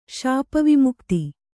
♪ śapa vimukti